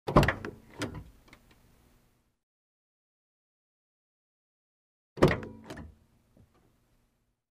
Звуки джипа
Звук открытия двери легкового авто снаружи при дерганьи ручки